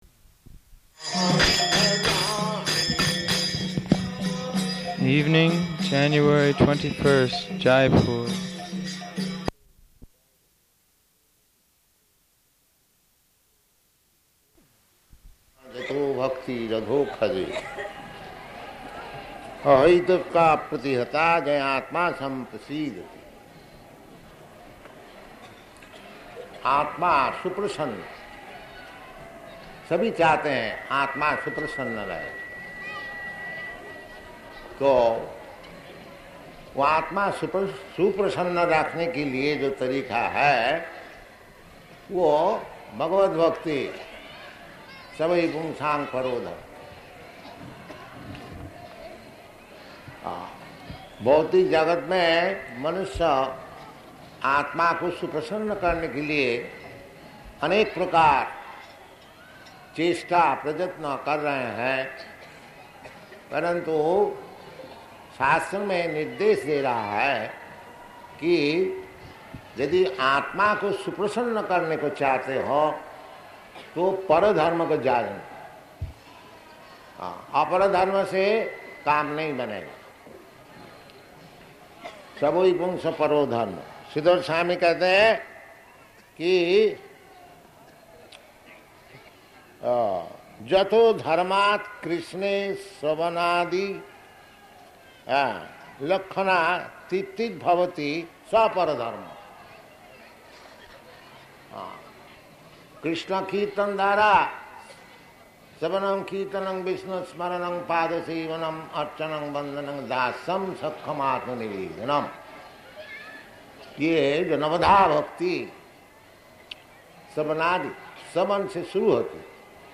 Lecture in Hindi
Lecture in Hindi --:-- --:-- Type: Lectures and Addresses Dated: January 21st 1972 Location: Jaipur Audio file: 720121LE.JAI.mp3 Devotee: [ kirtana ] Evening, January 21th, Jaipur.